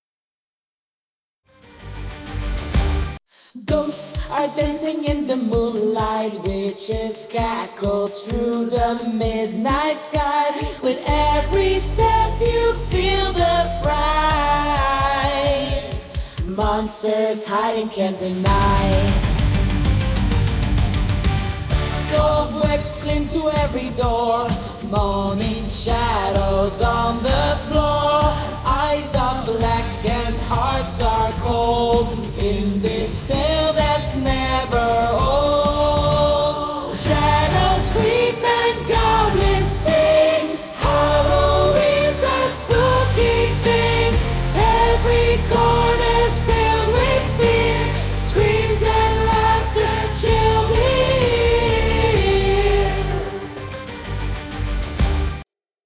Sopraan